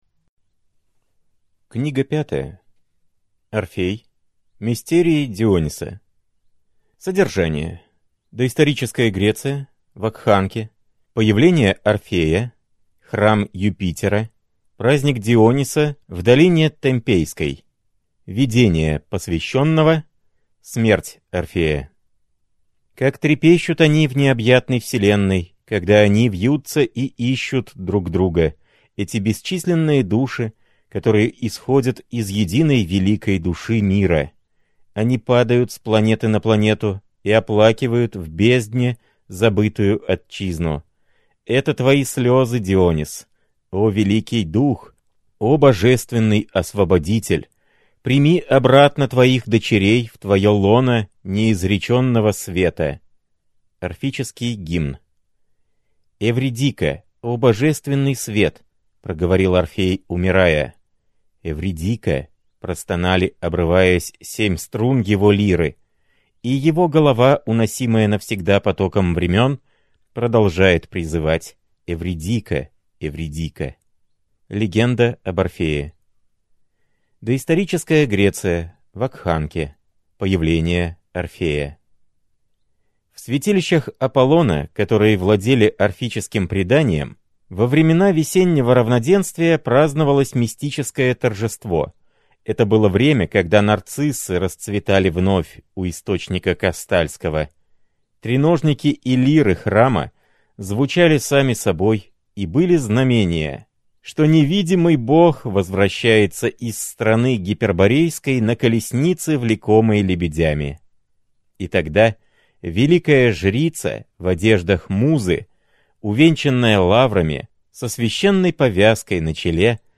Аудиокнига Орфей. Мистерии Диониса. Выпуск 5 | Библиотека аудиокниг